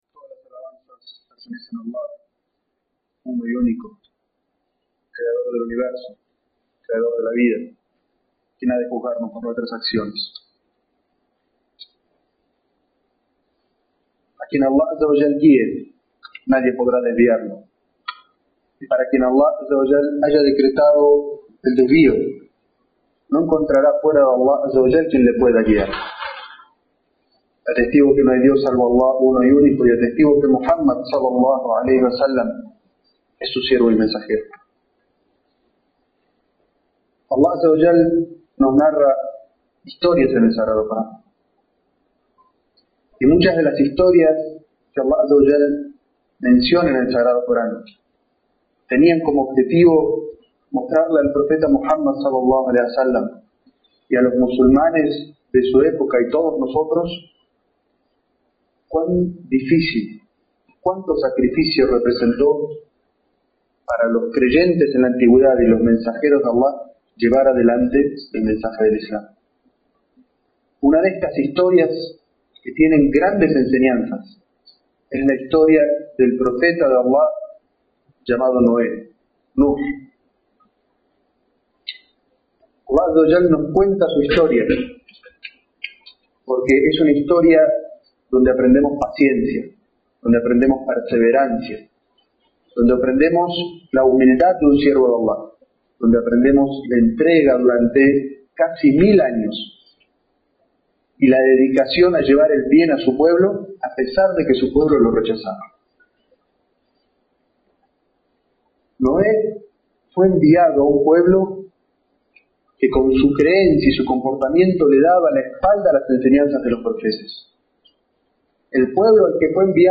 es-jutbah-la-historia-de-noe.mp3